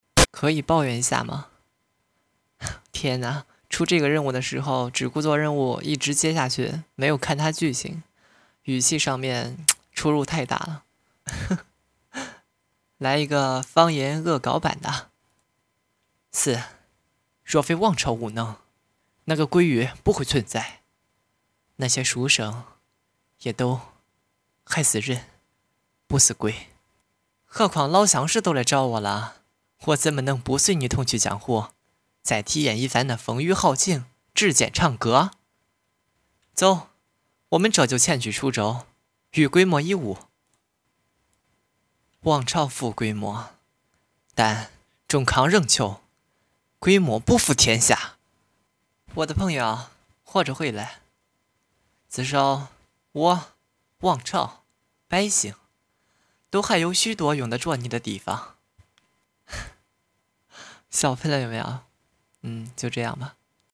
河南版：